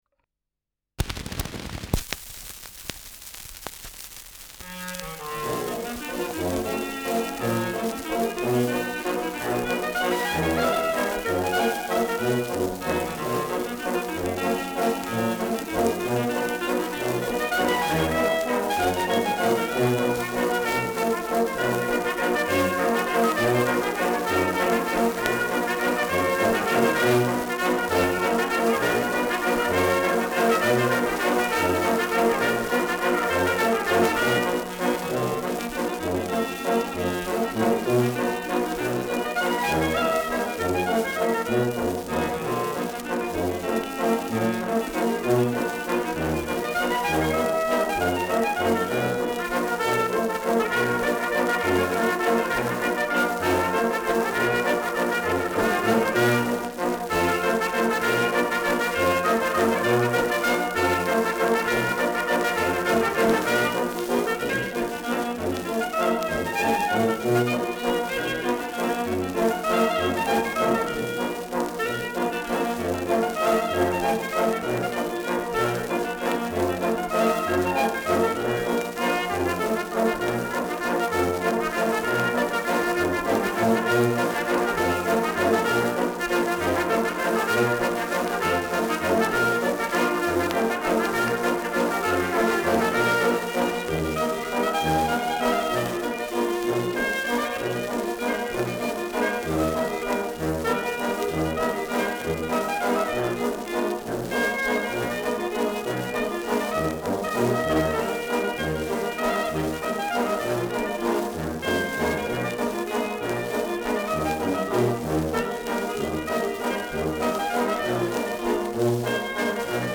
Schellackplatte
Tonrille: Kratzer 5 Uhr Stärker
Stärkeres Grundrauschen : Gelegentlich leichtes Knacken
[München] (Aufnahmeort)